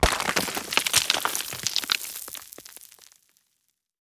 expl_debris_concrete_01.ogg